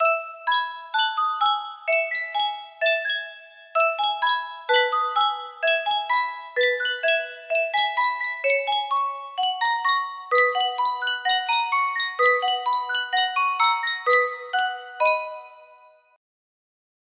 Yunsheng Music Box - Haydn Symphony No. 12 5820 music box melody
Full range 60